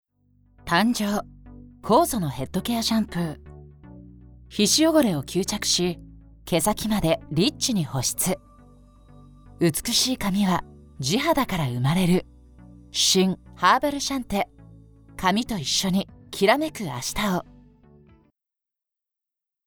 With a bright and easy listening voice, specialize in high-tension advertising videos.
– Narration –
Cosmetics commercial style